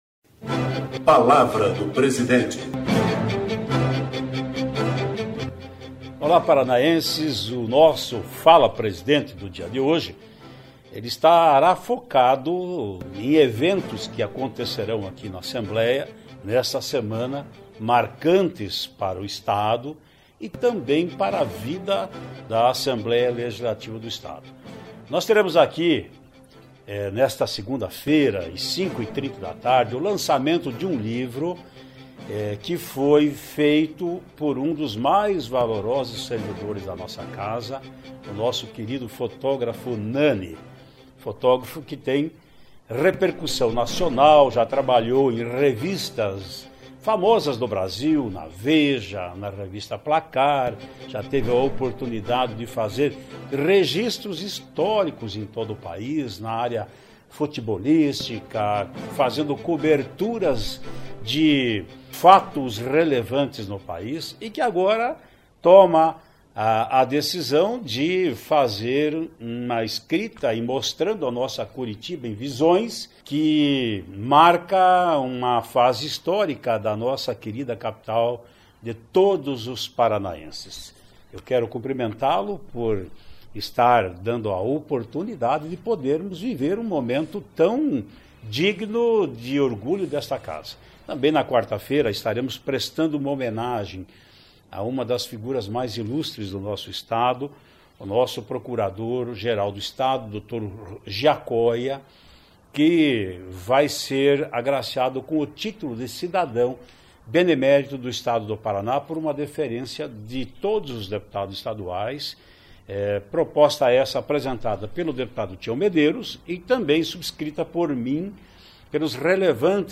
Ouça o que diz o rpesidente Ademar Traiano nesta  segunda-feira (28).